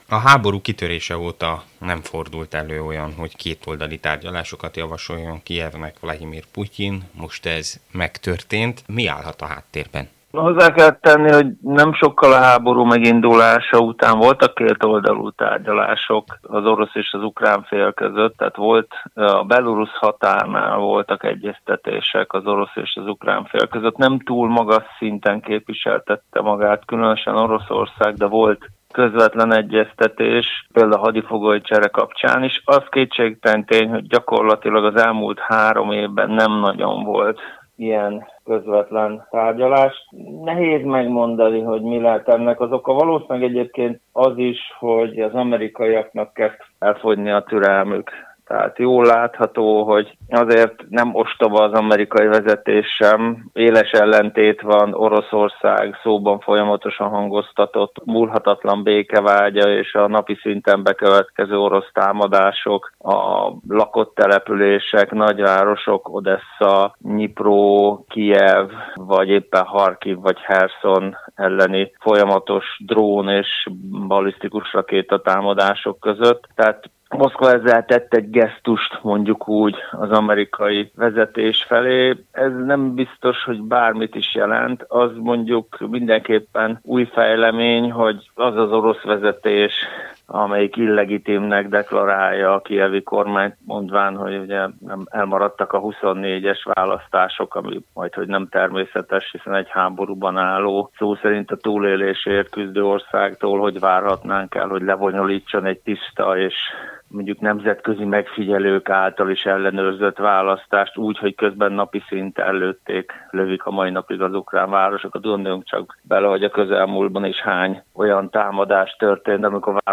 A Marosvásárhelyi Rádió által megkérdezett szakértő szerint ez azt jelzi, hogy kezd elfogyni az Egyesült Államok türelme.